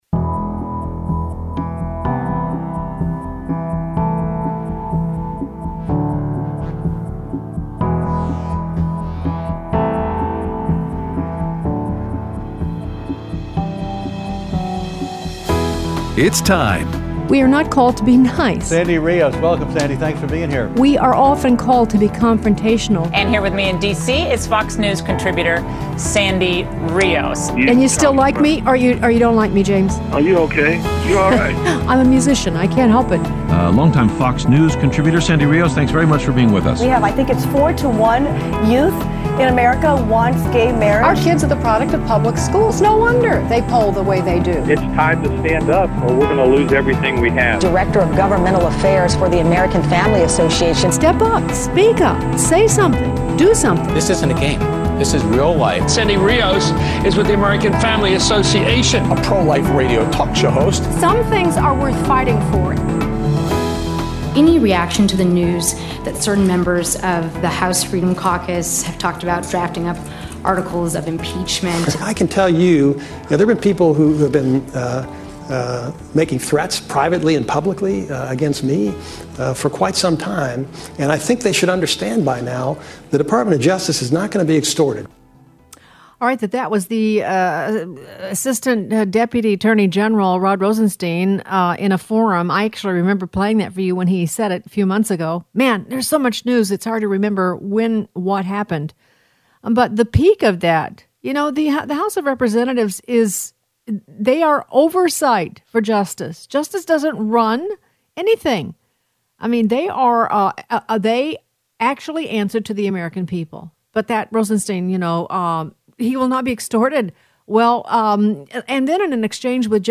Aired Thursday 7/26/18 on AFR 7:05AM - 8:00AM CST